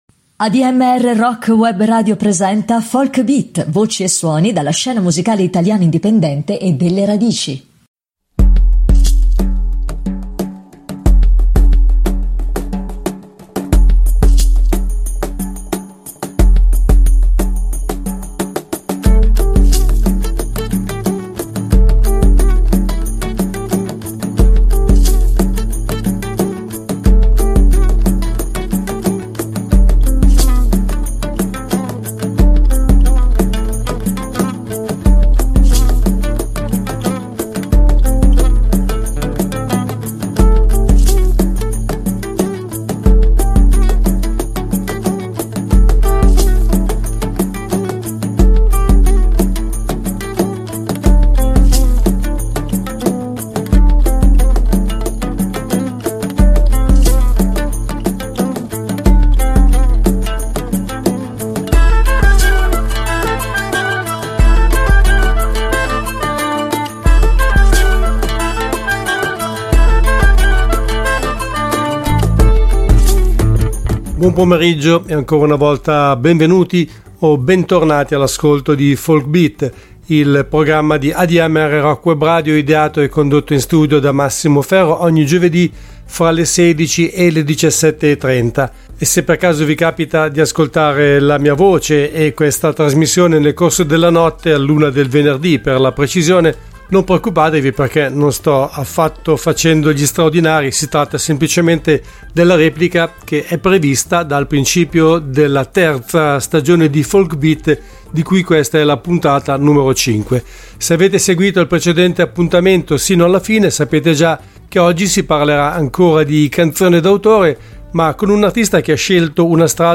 Part I: �Folk Beat� (19.10.2023) Ospite del programma al telefono